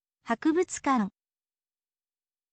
hakubutsu kan